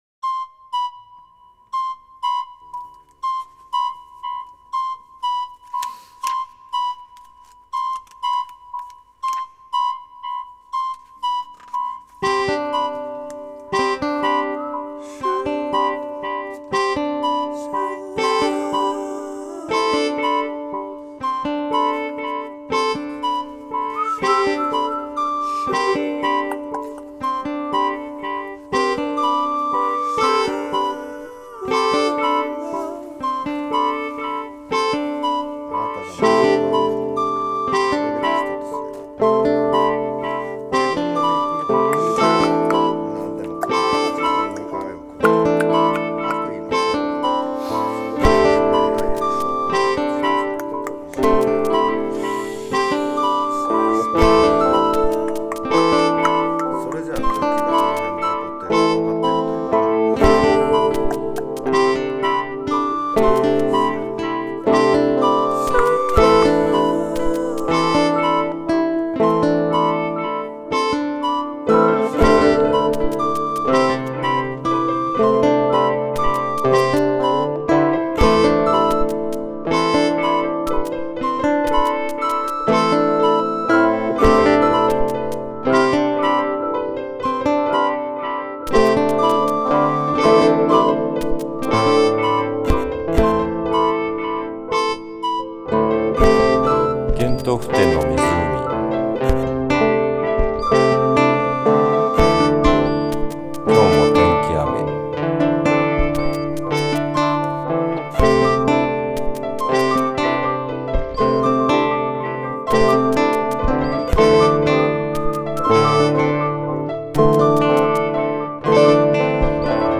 ギター、うた、笛、ピアノ、打楽器